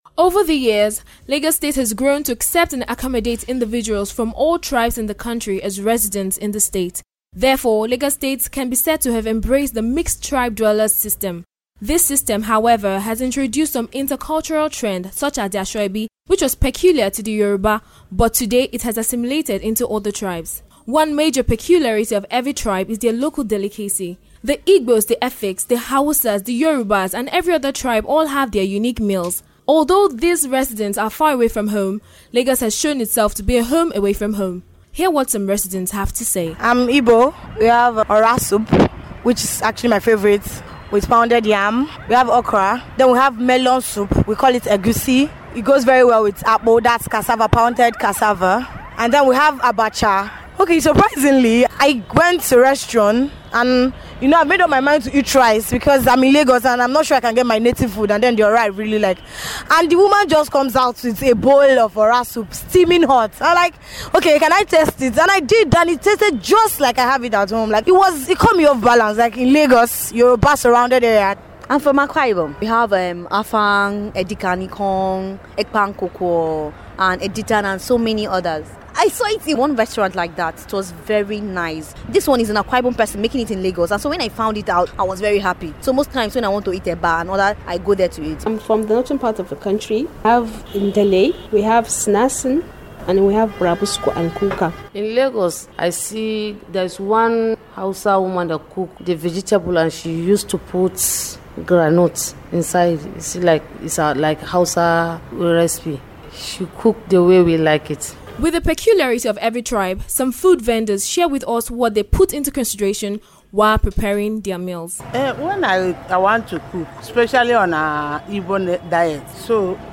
Listen to some people talk about their native delicacy.